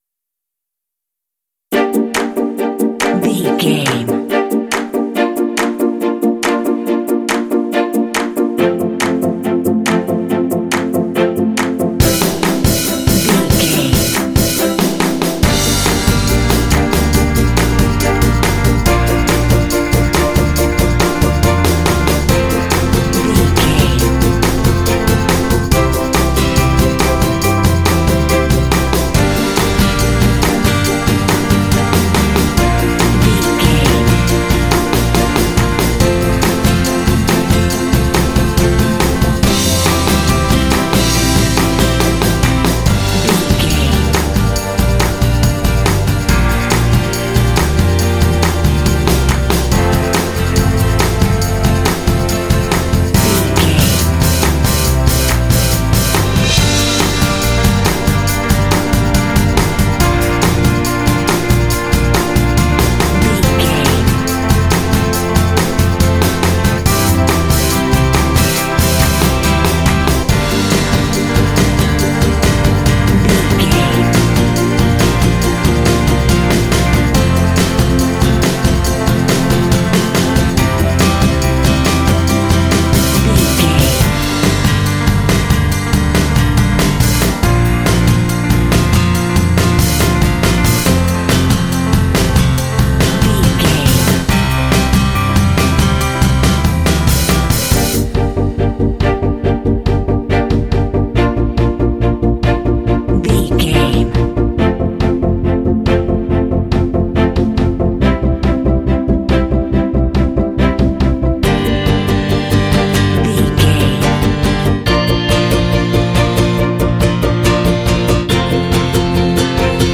Fast paced
In-crescendo
Dorian
Fast
energetic
cheerful/happy
strings
bass guitar
drums
synthesiser
piano
symphonic rock
cinematic
alternative rock